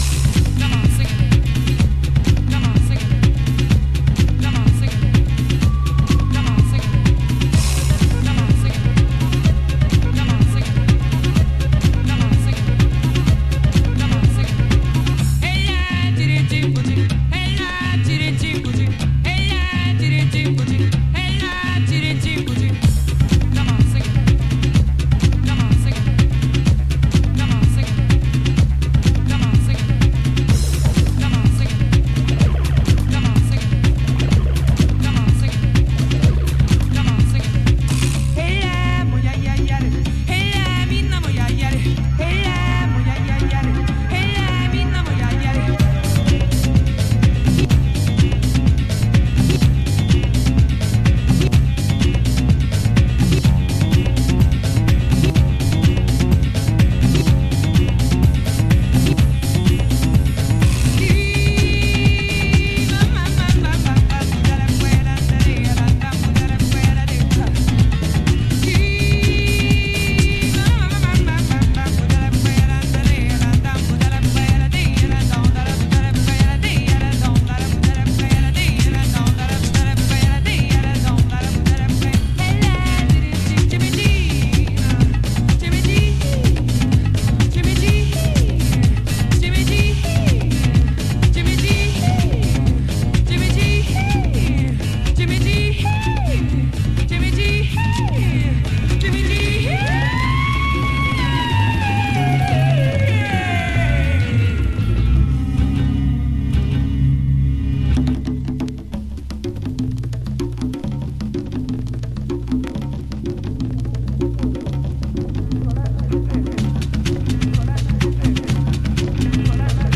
ラテンオーガニックグルーヴな